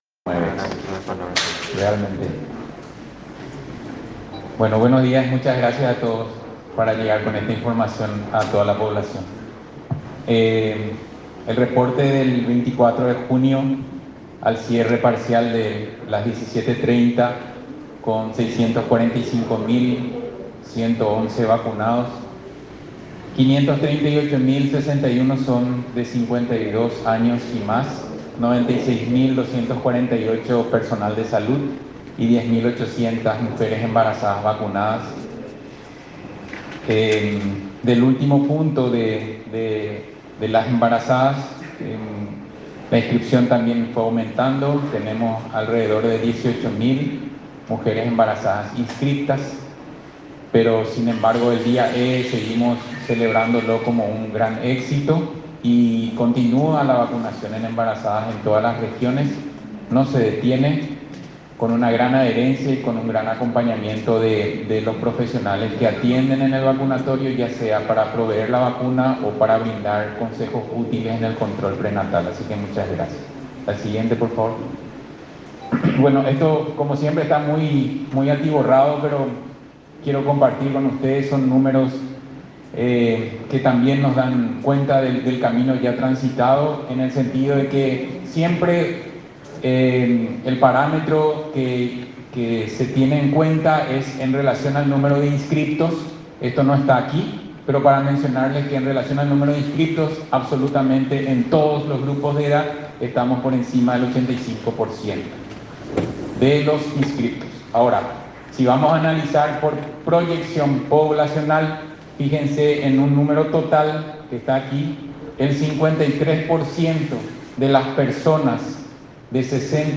Así lo anunció hoy el Dr. Héctor Castro, director del Programa Ampliado de Inmunizaciones (PAI), en conferencia de prensa semanal del Ministerio de Salud, quien mencionó que la distribución de terminaciones de cédula para que las personas accedan a las dosis es la siguiente: lunes 0, 1, 2 y 3; martes 4, 5 y 6; miércoles 7, 8 y 9.